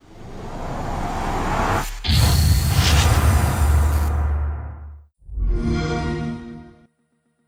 XBOX One X Startup.wav